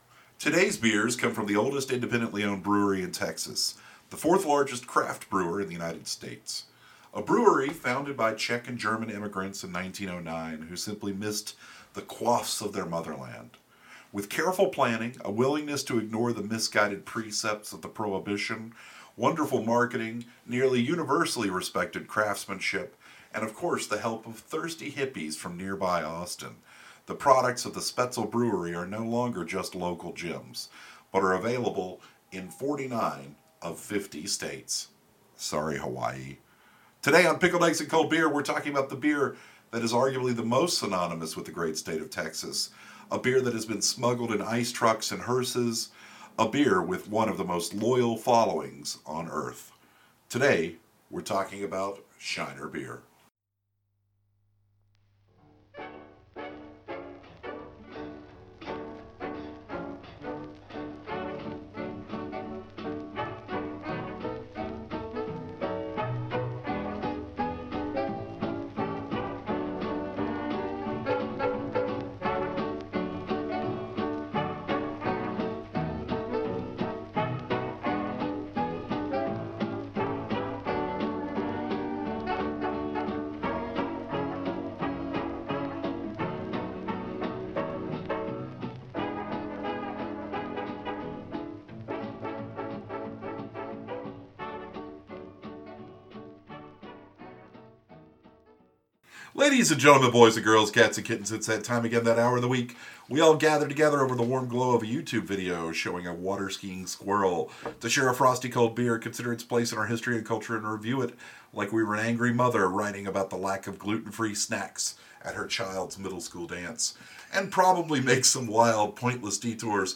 They laugh a lot.